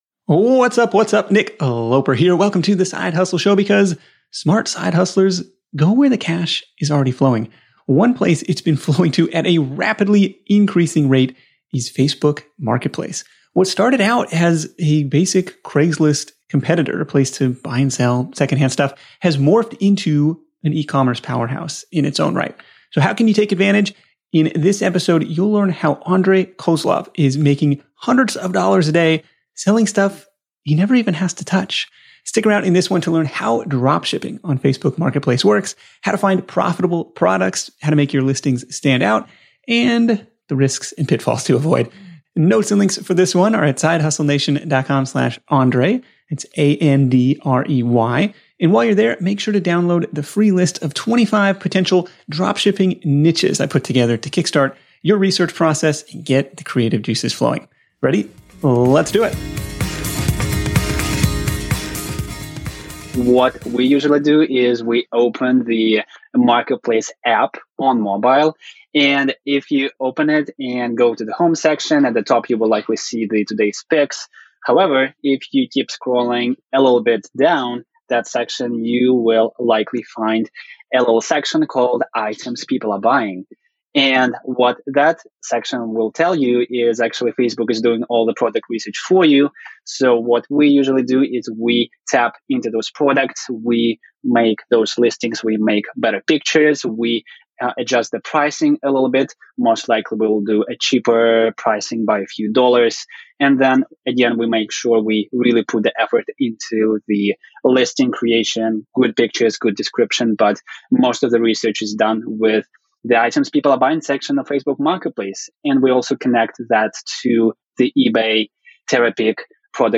The Side Hustle Show interview